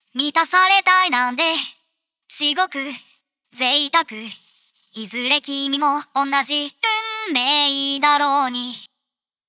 ボーカルサウンド試聴
Neve 88RLB使用後
08-Vocal-88RLB-After.wav